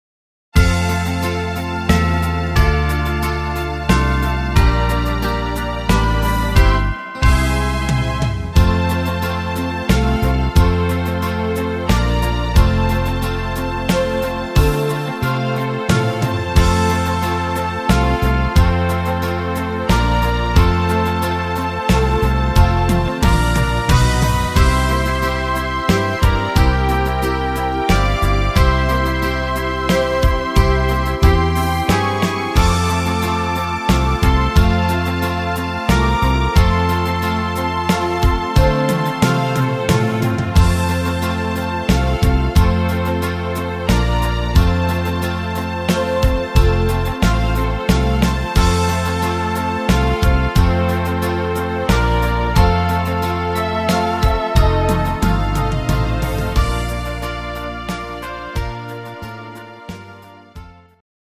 instr. Trompete